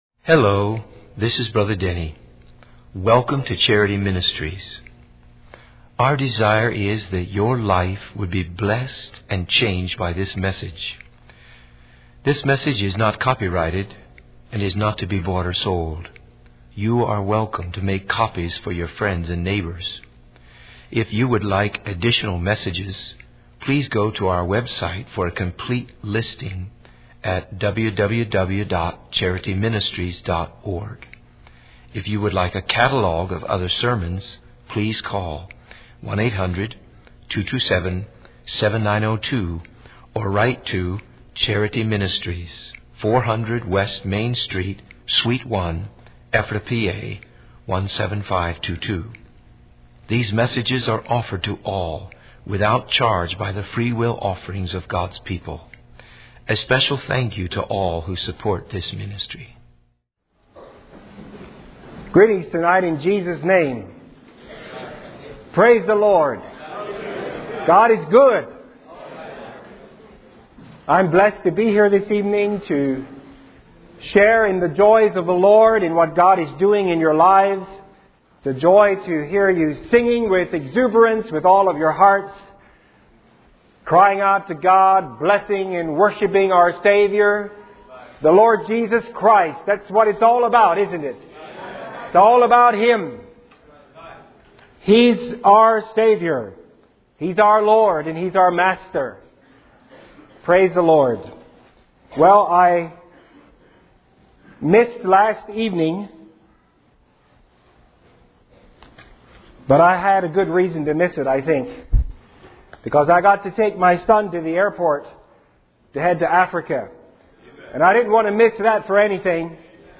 In this sermon, the preacher emphasizes the importance of being vessels that are transparent before God. He encourages the audience to have a purpose and motivation in life, to be sold out to God, and to be used by Him for His glory.